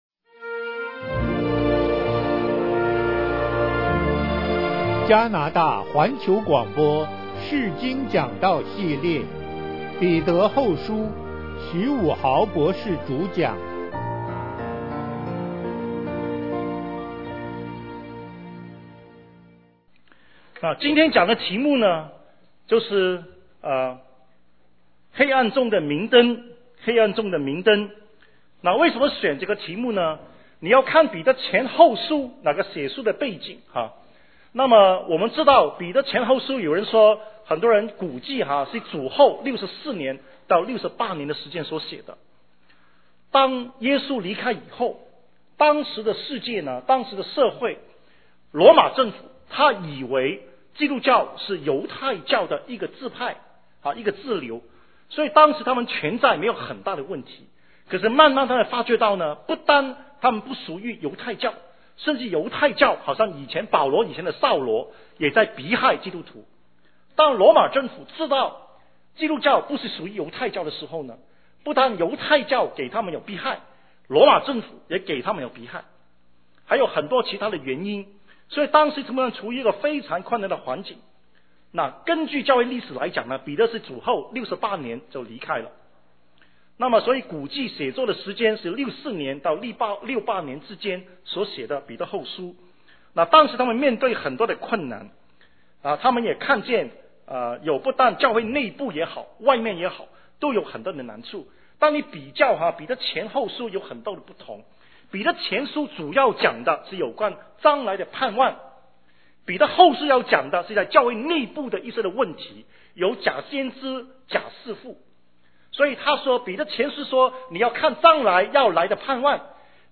講座錄音 彼得後書 一 彼得後書 二 彼得後書 三 彼得後書 四 講義手冊 彼得後書 – 講義 釋經講道系列 – 彼得後書